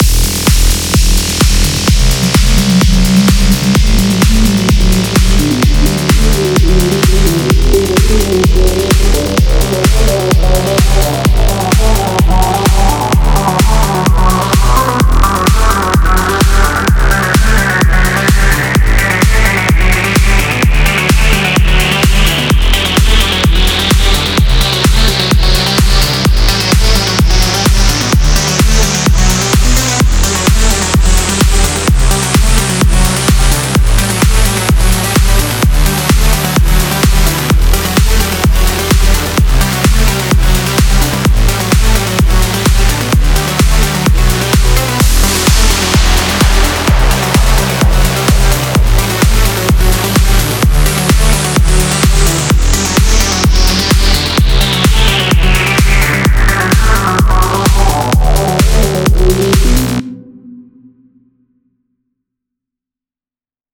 出来上がったデータを元に、トランス系のシンセ音源に差し替えて、キック、ベース、ハイハットを入れてどんな感じになるか試作してみます。
一通りトランスっぽい音でバランスを調整し、フィルターで音を変化させたりするとこんな感じになりました↓
PLUCK系の音の方が合っていたかもしれませんが、そこそこトランスっぽいフレーズパターンが出来上がりました。